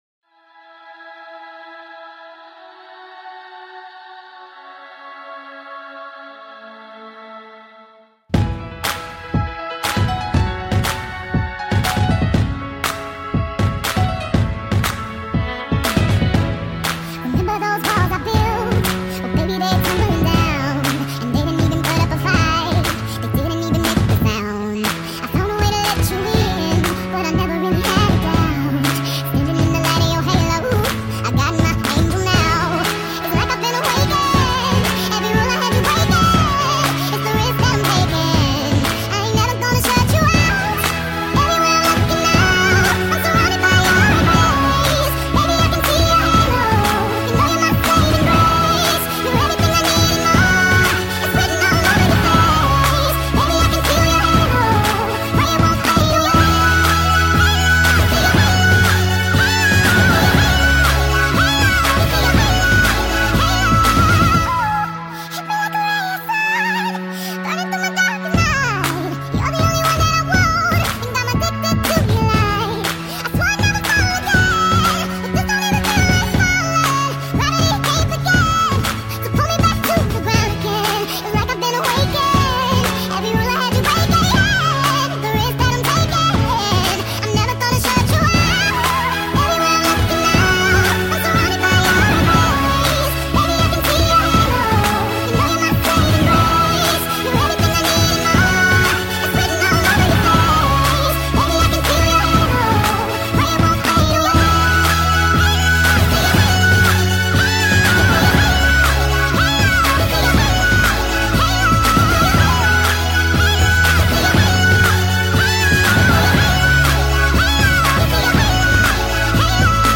sped up (1.5x)